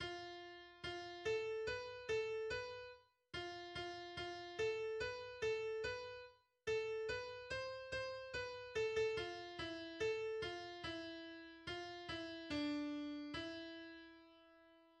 日本のわらべうた
{ \key c \major \time 2/4 \tempo 4=72 \relative c' { f4 f8 a8| b8 a8 b8 r8| f8 f8 f8 a8| b8 a8 b8 r8| a8 b8 c8 c8| b8 a16 a16 f8 e8| a8 f8 e4| f8 e8 d4| e2 \bar "|."